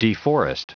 Prononciation du mot deforest en anglais (fichier audio)
Prononciation du mot : deforest